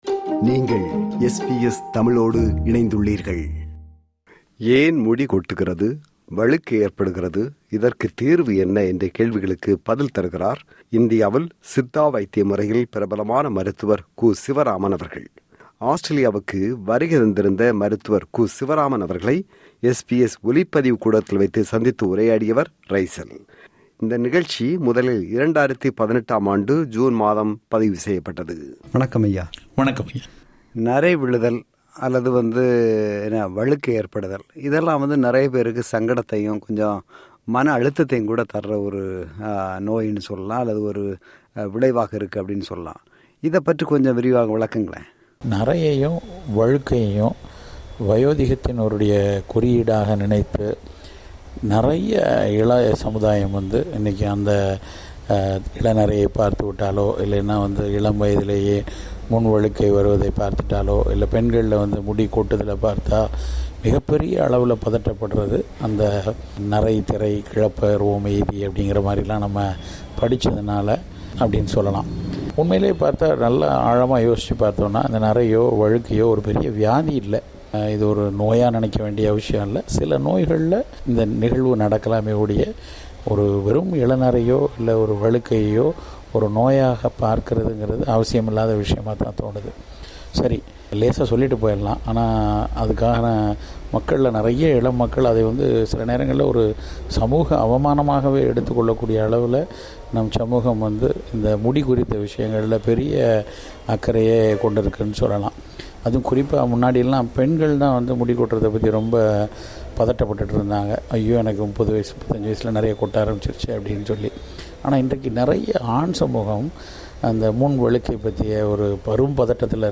SBS ஒலிப்பதிவு கூடத்தில்வைத்து